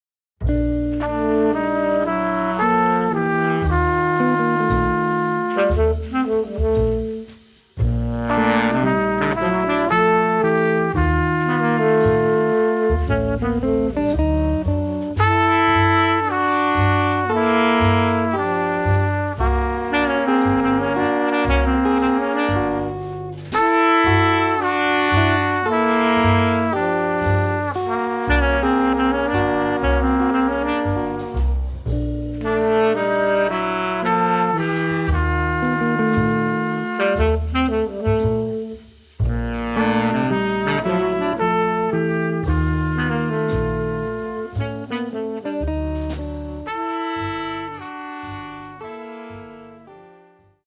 trumpet
sax
bass
drums
guitar